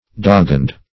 doggone \dog"gone\, doggoned \dog"goned\, adv.